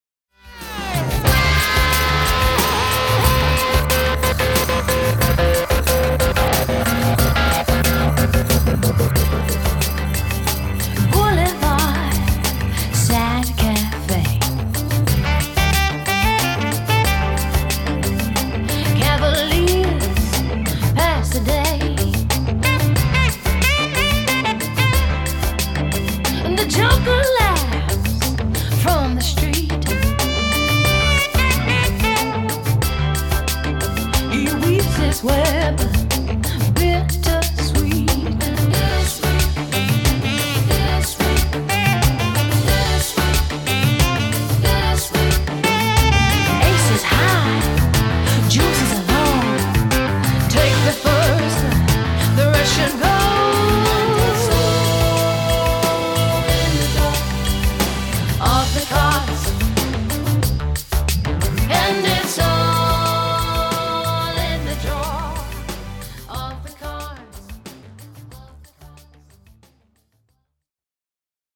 CLASSIC SONG COVERS
Organist
Backing Vocals…
All Keyboards and Guitars,
Solo d’orgue gothique,
Fender ’63 Precision Bass and